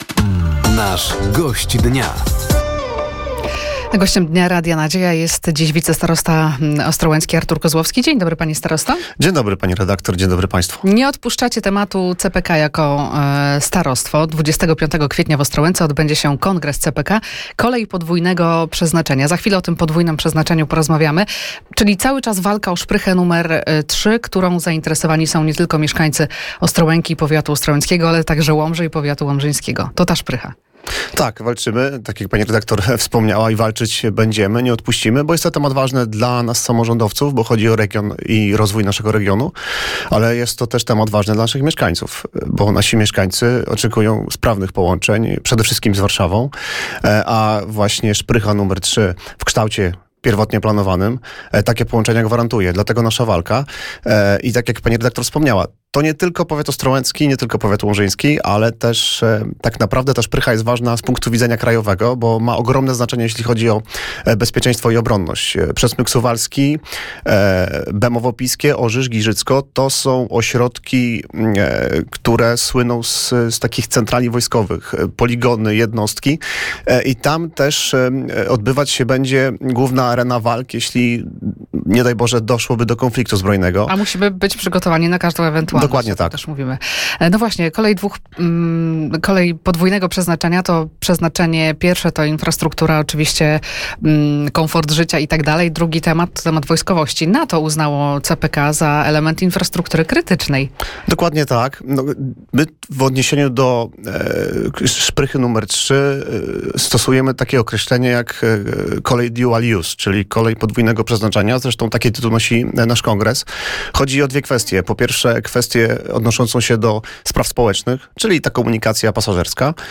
Walka samorządowców i parlamentarzystów o szprychę nr 3, a także zbliżający się ogólnopolski Kongres ,,CPK – Kolej podwójnego przeznaczenia” organizowany w Ostrołęce – to główne tematy rozmowy z piątkowym (17.04) Gościem Dnia Radia Nadzieja, którym był Artur Kozłowski, wicestarosta ostrołęcki.